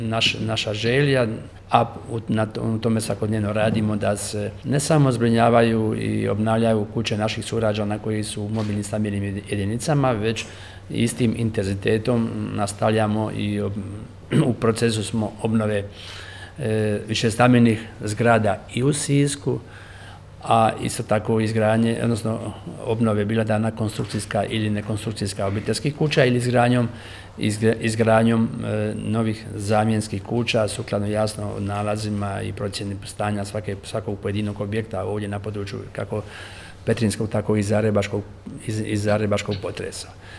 Ovo je bio jedan od tih načina, rekao je potpredsjednik Vlade RH, ministar Branko Bačić te istaknuo